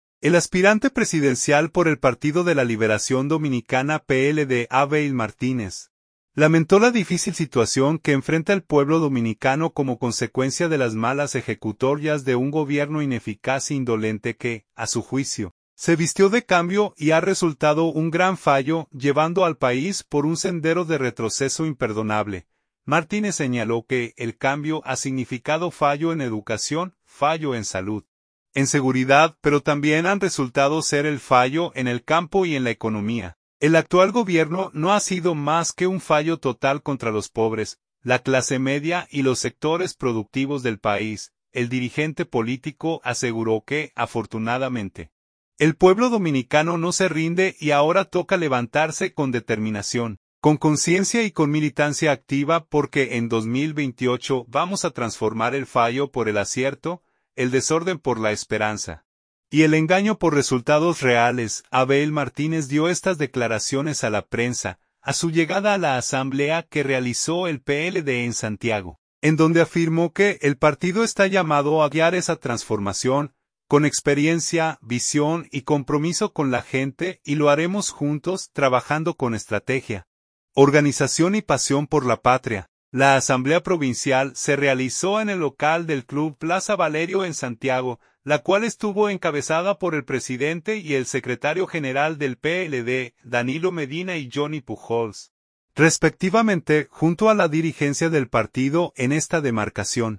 Abel Martínez dio estas declaraciones a la prensa, a su llegada a la asamblea que realizó el PLD en Santiago, en donde afirmó que “el partido está llamado a guiar esa transformación, con experiencia, visión y compromiso con la gente, y lo haremos juntos, trabajando con estrategia, organización y pasión por la patria”.